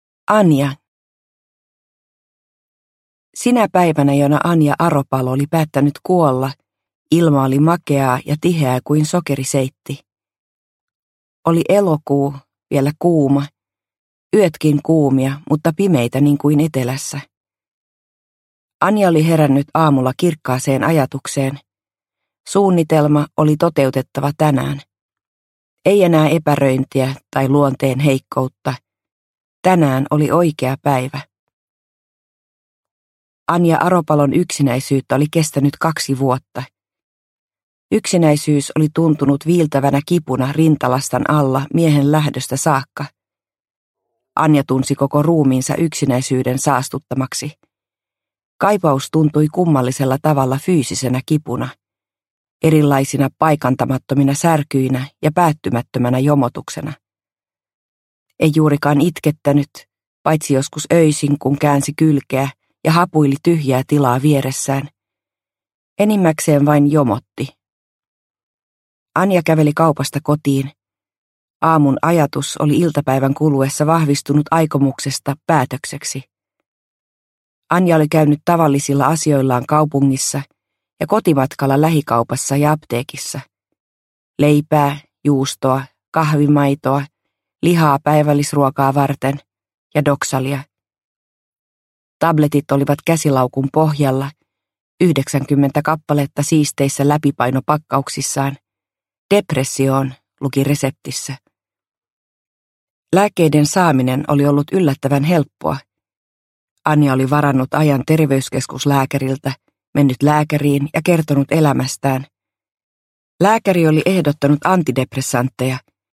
Raja – Ljudbok – Laddas ner